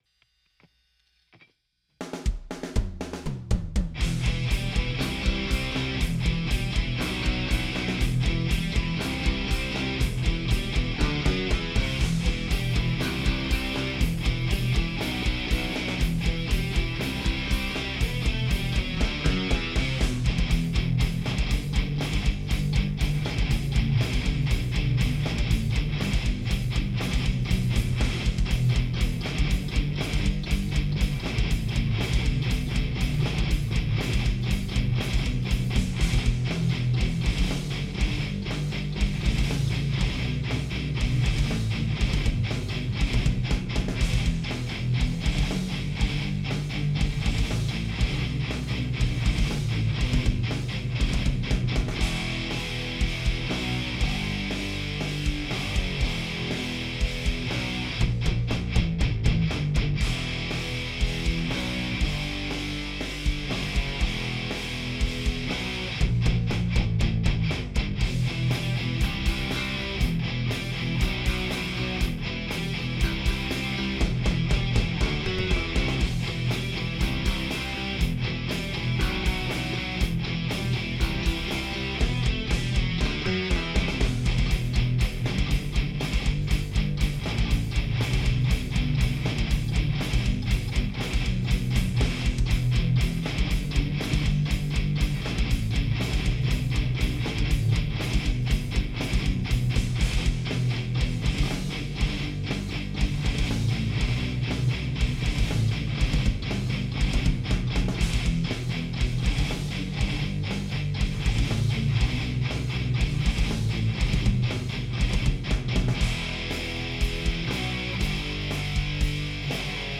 Recording this mess Saturday with the usual DSL/Mesa Cab.
Definitely a ways to go, but I found I'm not so gun-shy about letting the low end exist now.
Low end sounds good in my studio monitors but when I listen through headphones there's something off with the panning.
The one part I hear a difference is the big palm-muted riff, where it's left side has the low note, right side has the high note, and center has the chord formed by those notes.